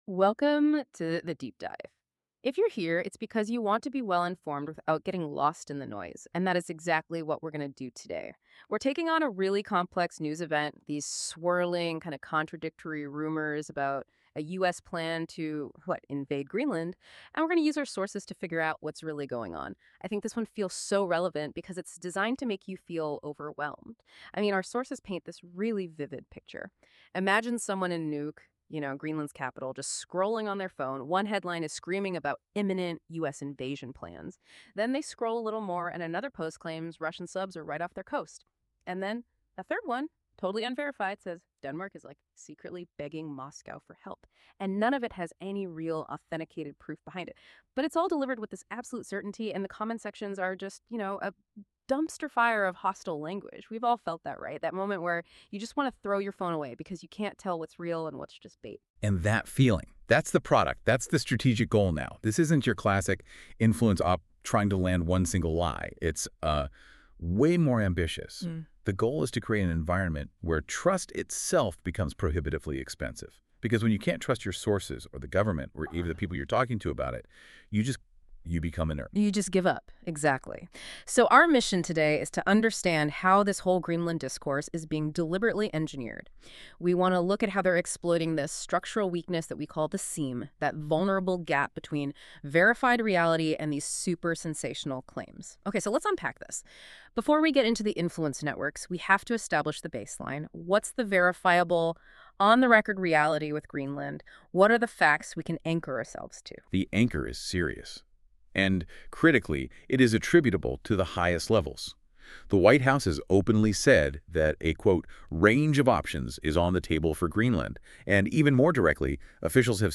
Related Podcasts These podcast episodes are produced with AI voice technology. While we strive for accuracy, please be aware that the voices and dialogue you hear are computer-generated. Weaponizing Greenland Rumors to Break NATO 00:00 / 13:23